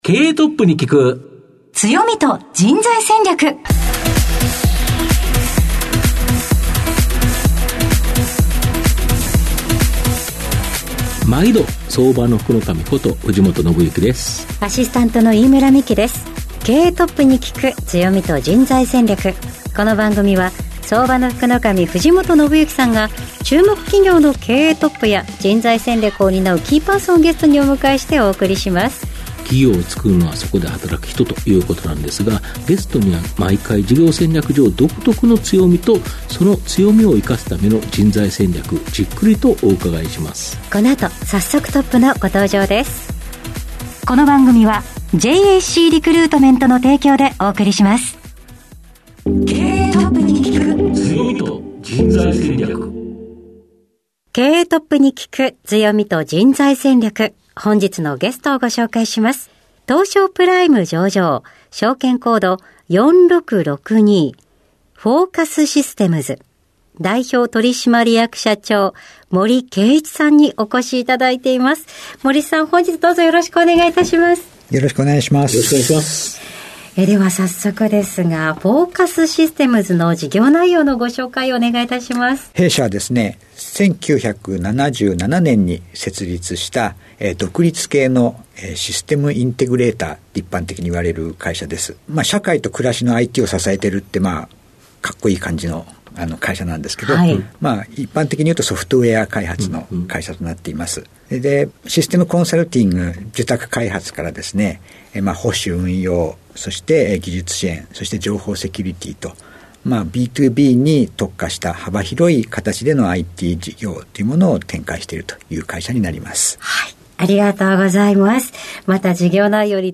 毎回注目企業1社をピックアップし経営トップをゲストにお招きし、事業の側面だけでなく人材戦略の観点からも企業の強みに迫る。トップの人柄が垣間見えるプライベートなQ&Aも。